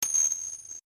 bellSound.wav